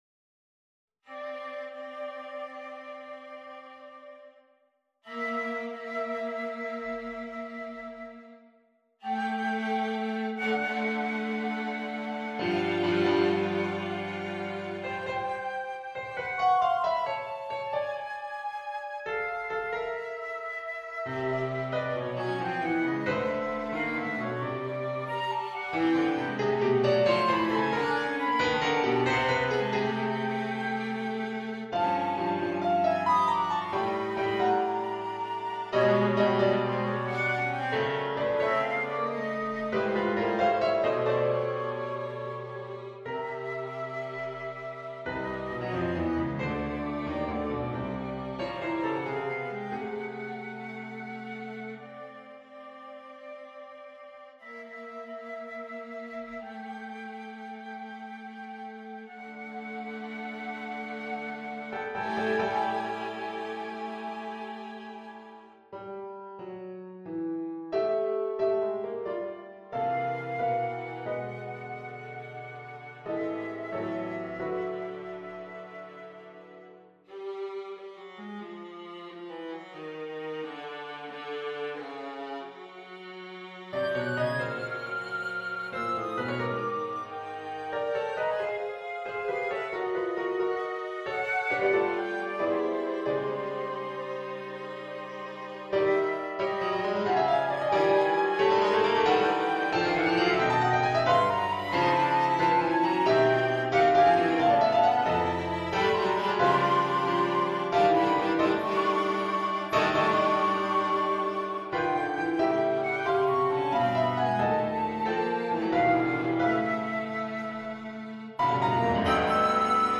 Movement_for_flute_viola_and_piano.mp3